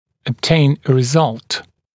[əb’teɪn ə rɪ’zʌlt][эб’тэйн э ри’залт]получать результат